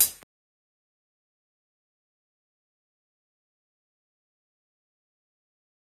Hihat ($quad).wav